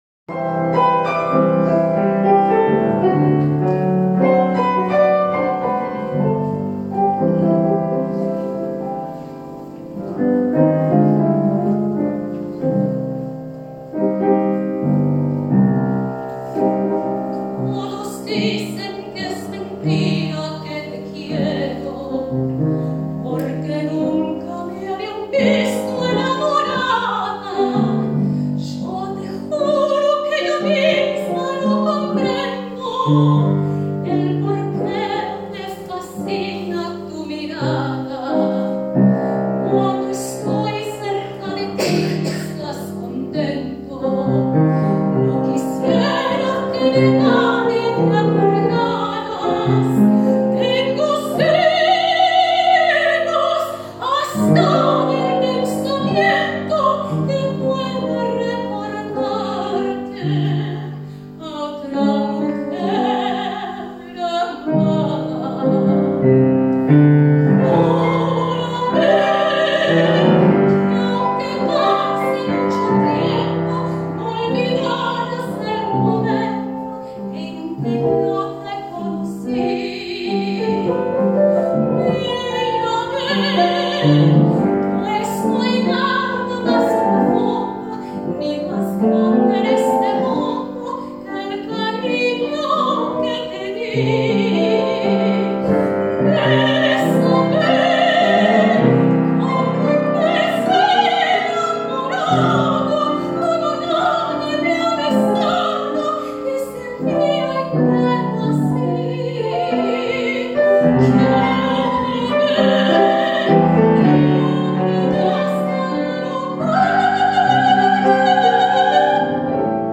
Soprano
Piano; Benefit Concert for Women in Iran and the Victims of the Earthquakes in Syria and Turkey, Bösendorfersaal, Mozarteum, February 28, 2023